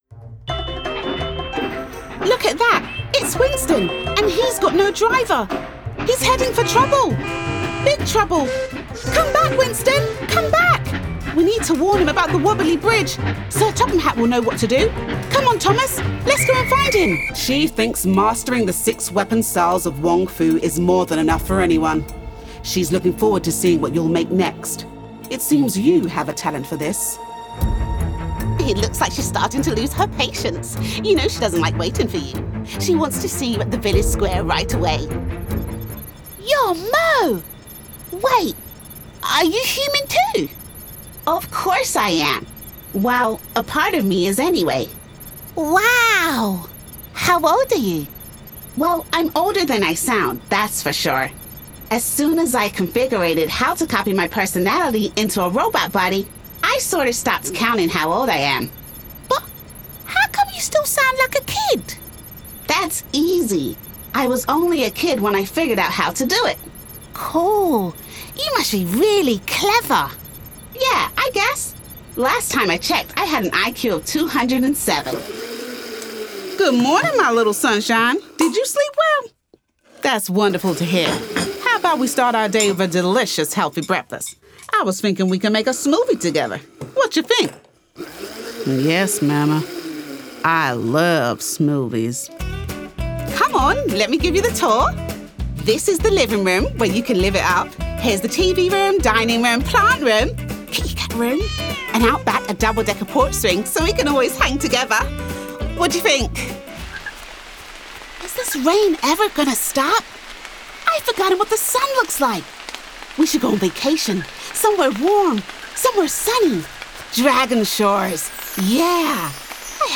Cool, Street, London and Friendly
Games & Animation Reel
Acting, Emotions, Versatile, Energetic, Diverse, Games, Characters
American, Jamaican, RP ('Received Pronunciation'), South London, Well Spoken